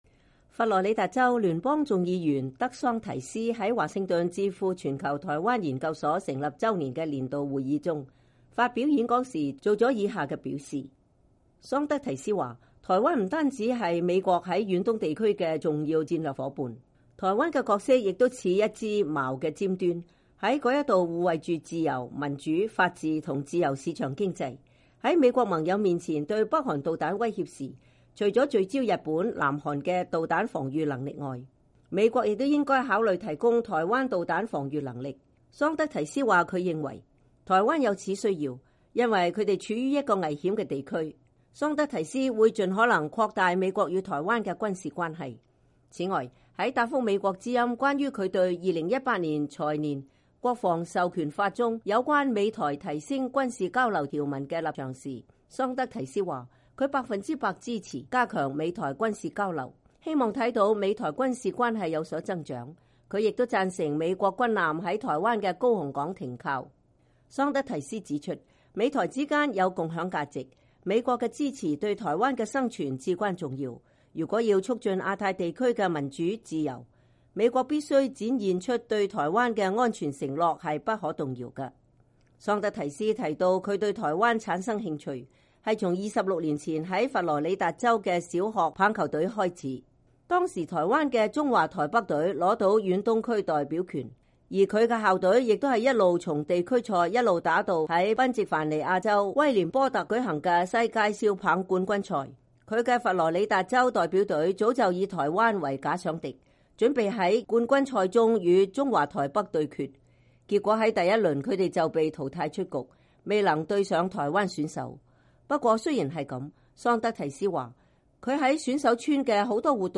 佛羅里達州聯邦眾議員德桑提斯在華盛頓智庫全球台灣研究所成立週年的年度會議中發表演說時作出上述表示。